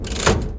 Lever3.wav